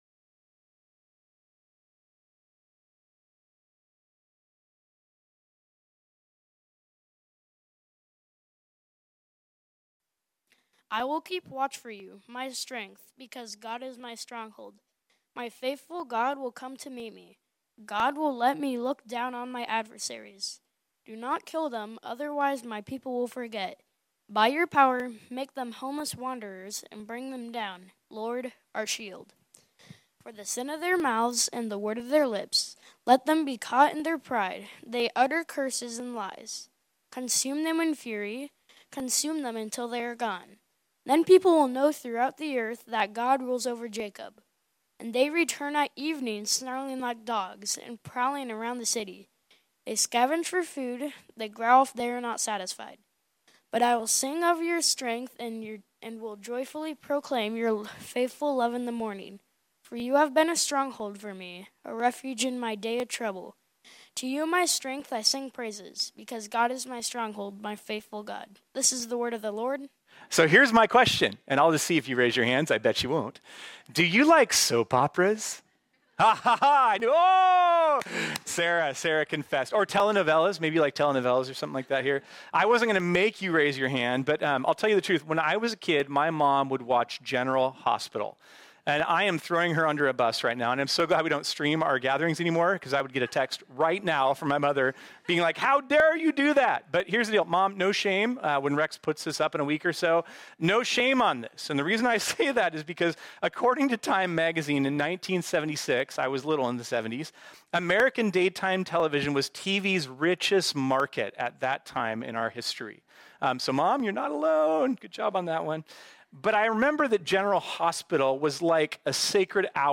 This sermon was originally preached on Sunday, May 28, 2023.